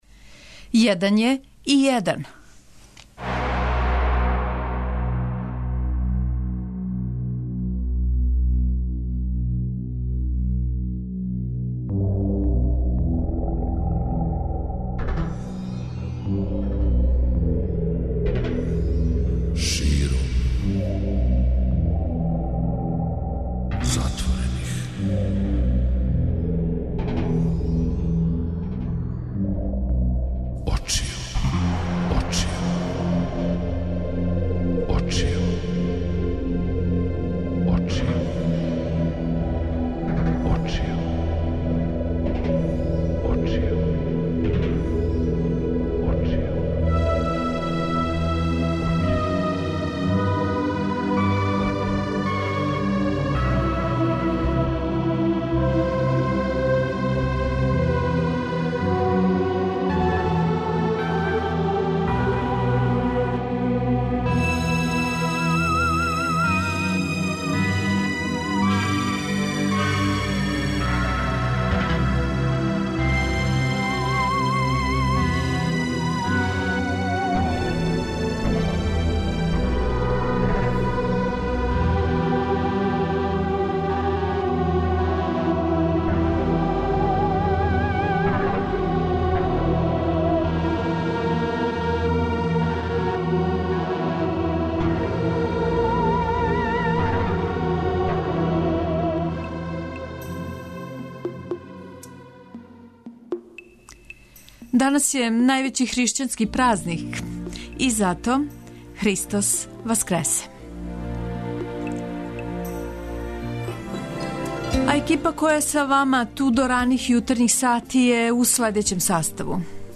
преузми : 85.45 MB Широм затворених очију Autor: Београд 202 Ноћни програм Београда 202 [ детаљније ] Све епизоде серијала Београд 202 Устанак Блузологија Свака песма носи своју причу Летње кулирање Осамдесете заувек!